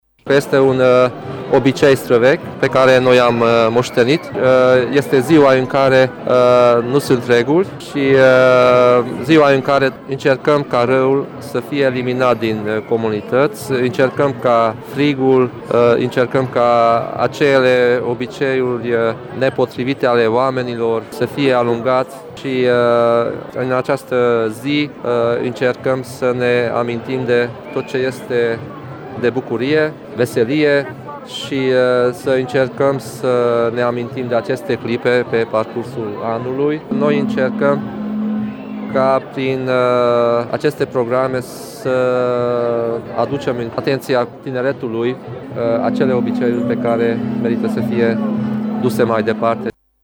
Prezent la eveniment, preşedintele Consiliului Judeţean Harghita, Borboly Csaba, a explicat că evenimentul este unul foarte vesel şi zgomotos, în cadrul căruia sute de persoane poartă diverse costume şi ridiculizează defectele şi viciile omeneşti şi proastele obiceiuri, la care trebuie să renunţe la intrarea în postul Paştelui.